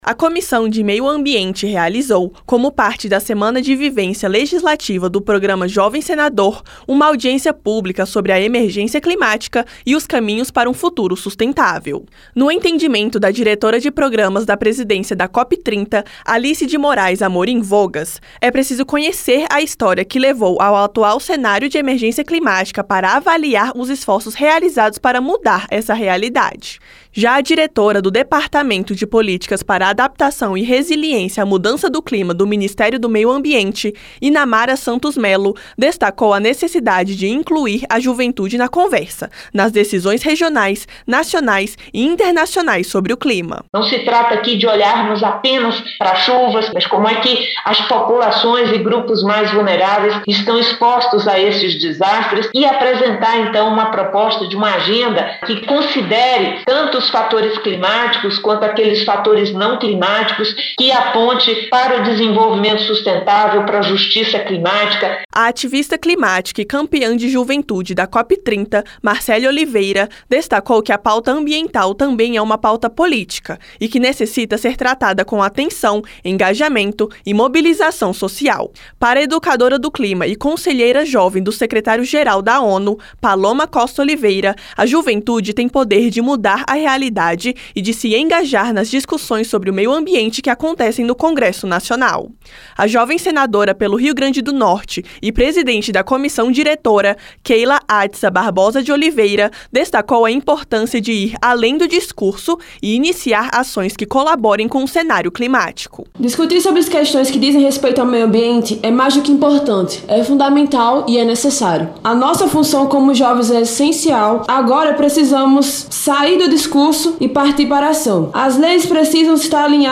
Como parte da Semana de Vivência Legislativa do Programa Jovem Senador, a Comissão de Meio Ambiente promoveu nesta quinta-feira (21) uma audiência pública para debater a emergência climática e os caminhos para um futuro sustentável. Durante o debate, vários participantes ressaltaram a importância de os jovens se engajarem nos debates sobre o assunto.